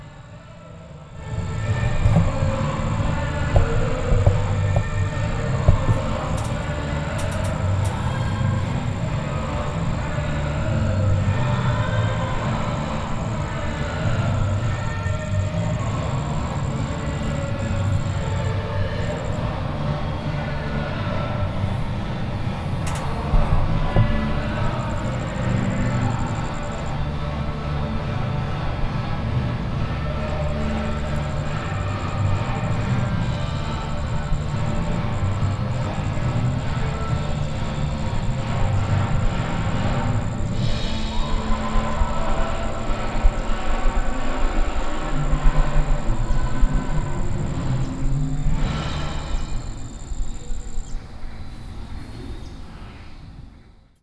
This is how the No Kings protest sounds on the town common, almost a mile from here**.
** The music was coming from an event not directly associated with the No Kings rally.